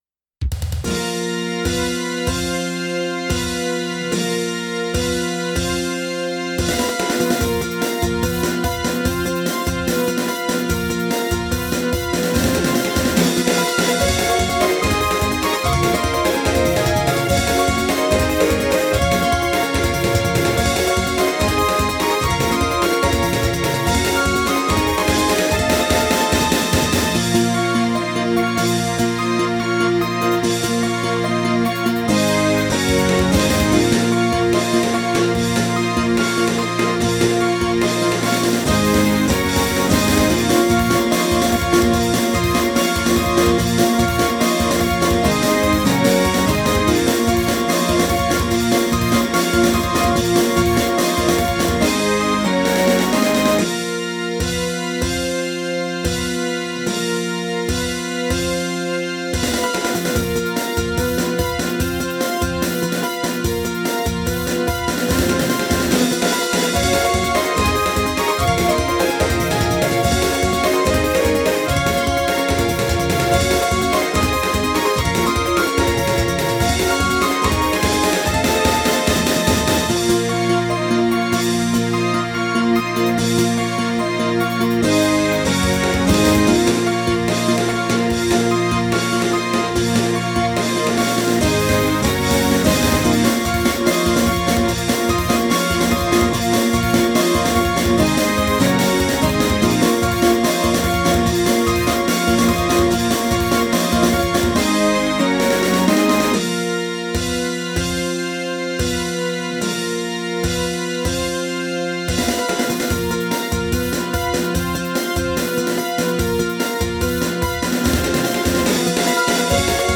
由Roland Sound Canvas 88Pro实机录制